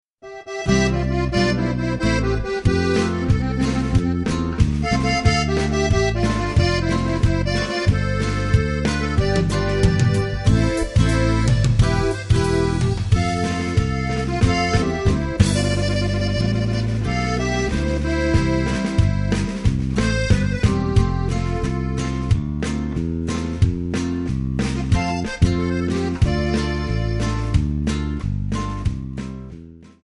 Backing track files: Ital/French/Span (60)